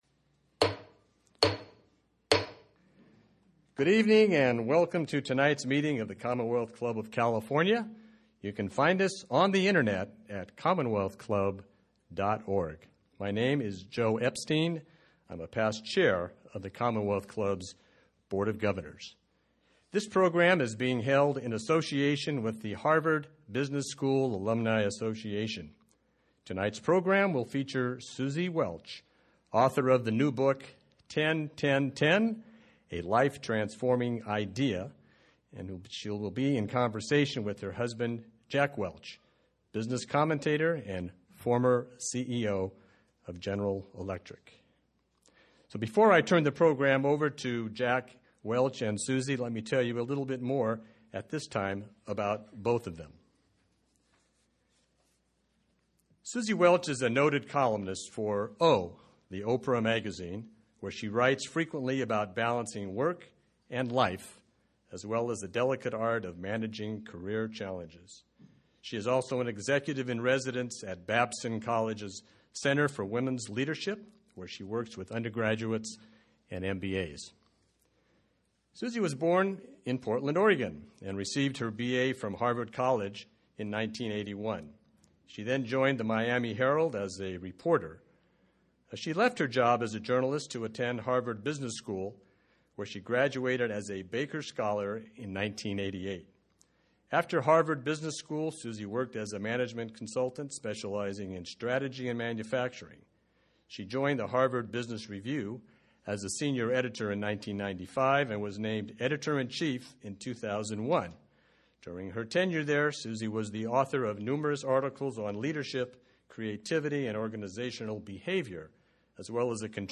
Suzy Welch in Conversation with Jack Welch